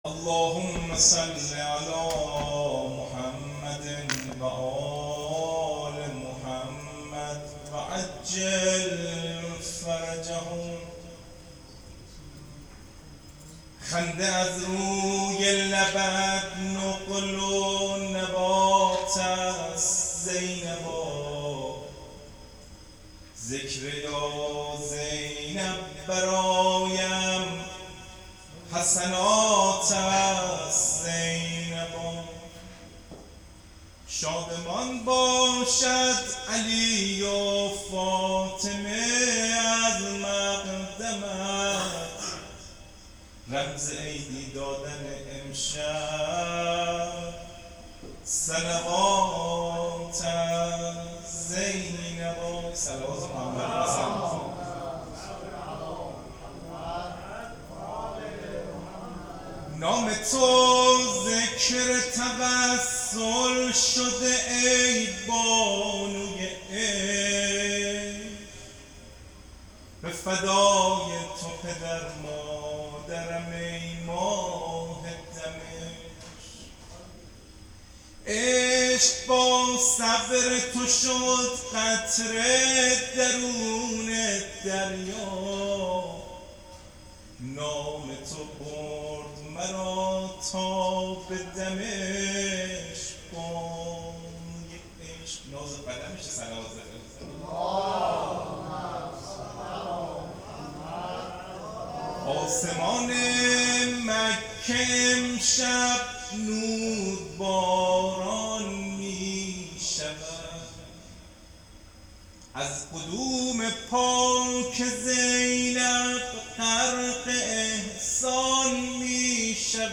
مدح میلاد حضرت زینب س